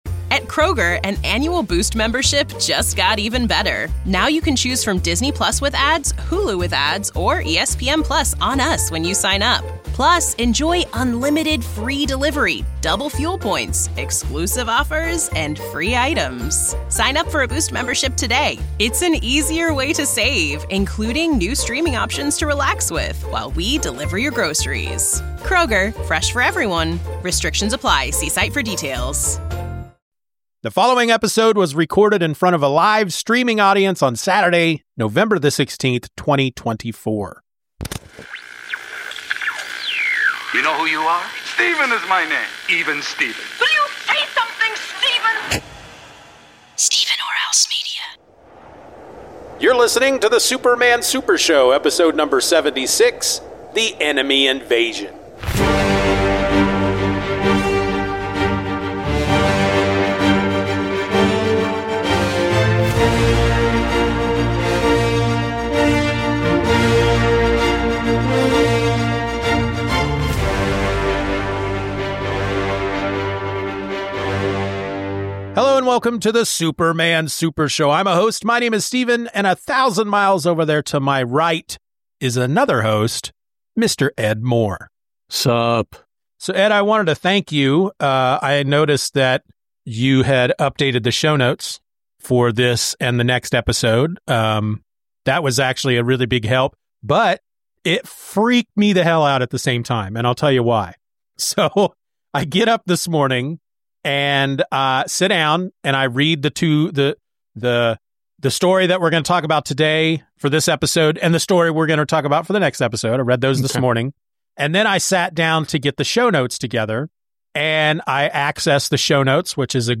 two regular guys separated by half the United States but united by their love of comics